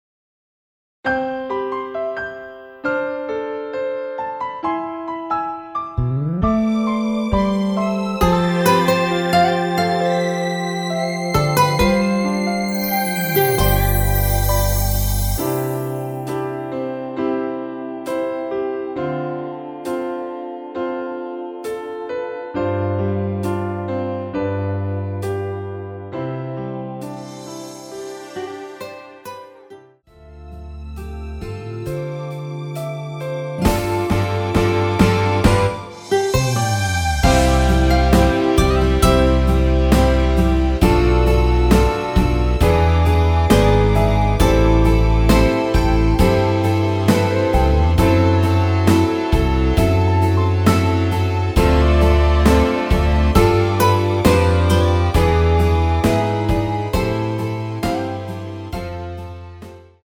원키에서(+3)올린 MR입니다.
앞부분30초, 뒷부분30초씩 편집해서 올려 드리고 있습니다.